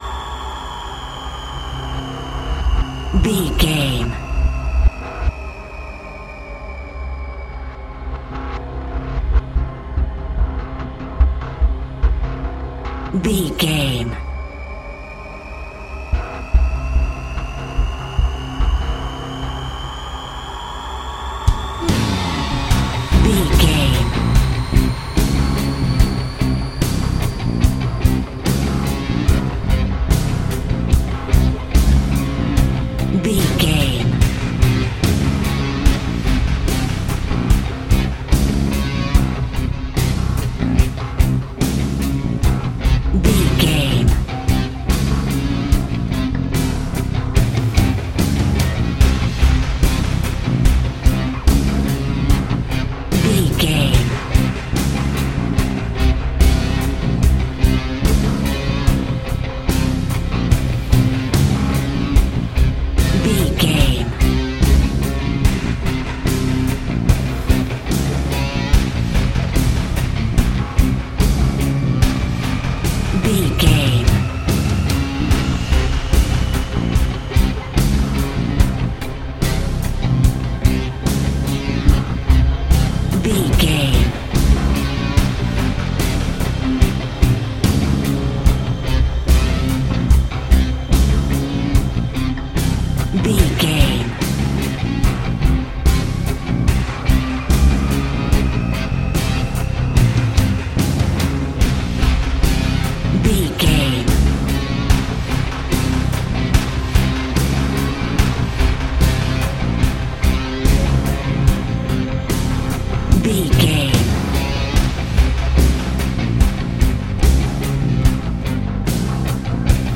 Thriller
Aeolian/Minor
synthesiser
ominous
dark